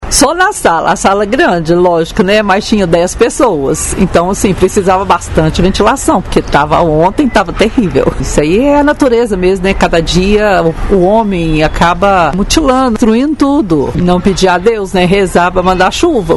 Nossa reportagem conversou com uma ouvinte, que preferiu não ter a identidade revelada. Ela confidenciou que na tarde de domingo precisou manter 4 ventiladores ligados ao mesmo tempo na sala de casa.
Ouvinte